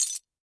GLASS_Fragment_08_mono.wav